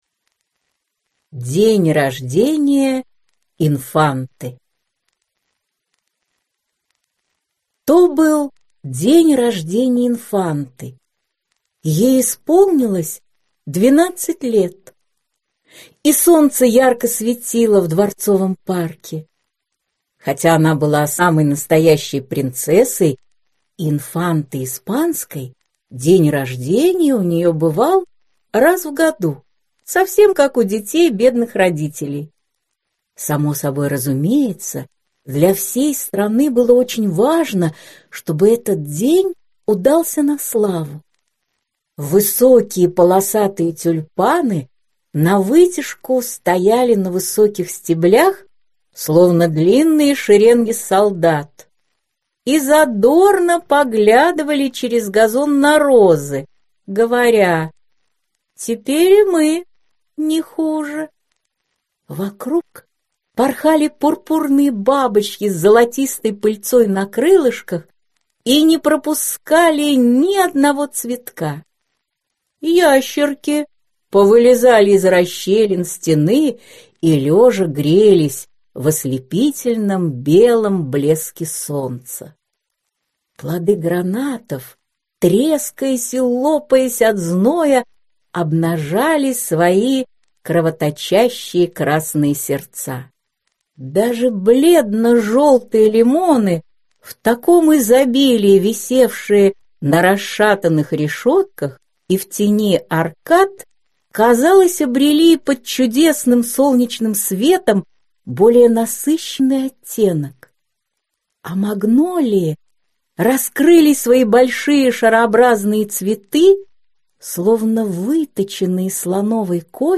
Аудиокнига День рождения инфанты. Сборник сказок | Библиотека аудиокниг